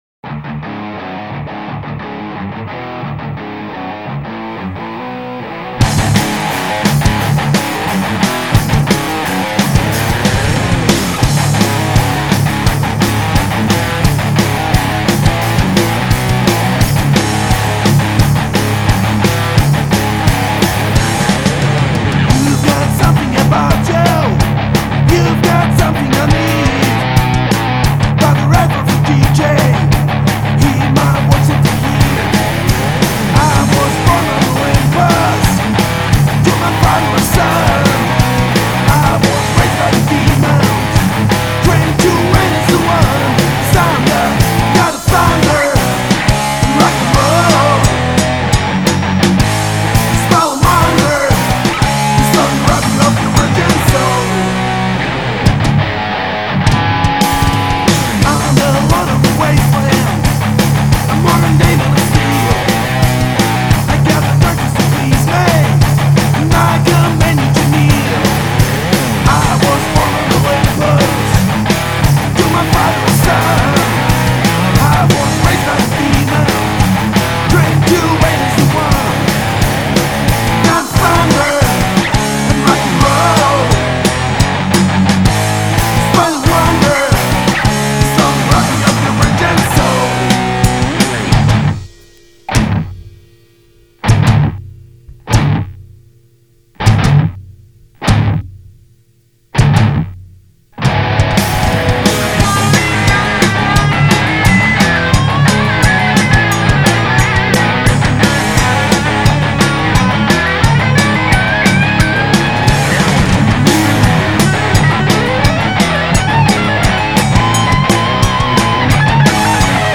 Aktuelle Proberaumdemo